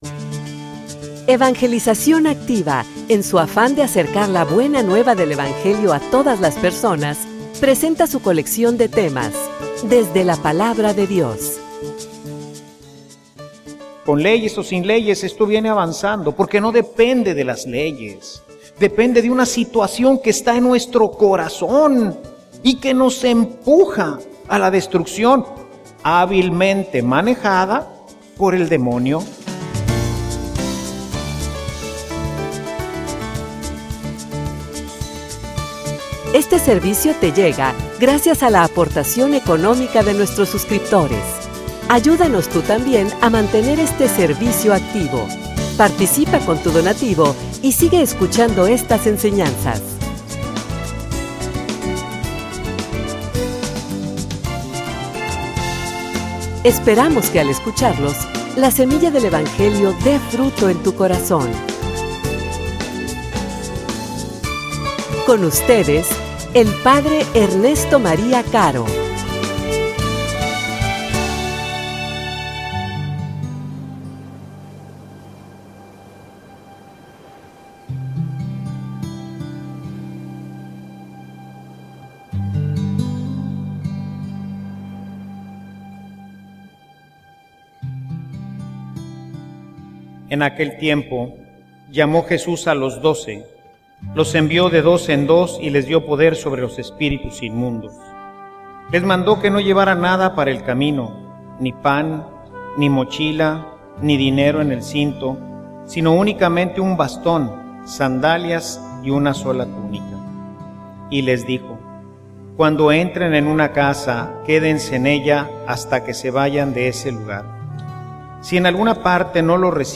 homilia_No_soy_profeta_ni_hijo_de_profeta.mp3